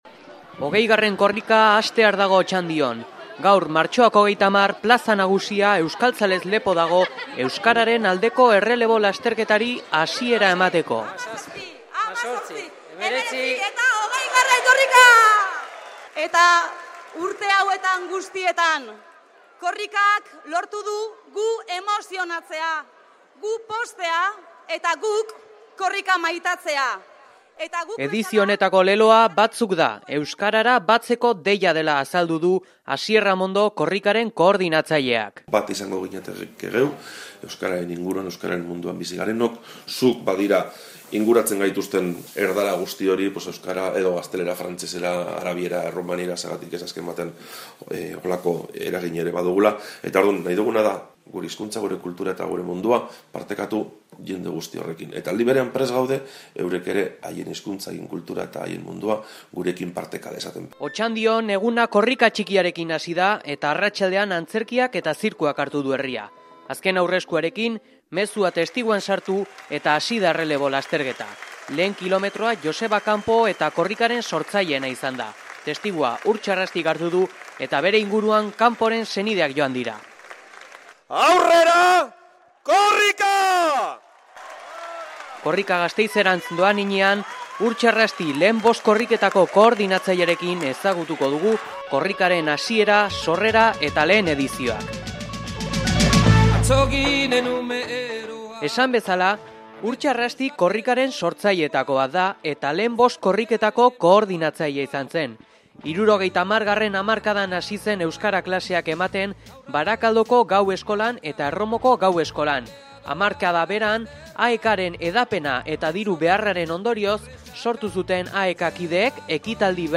Documento sonoro de Korrika 20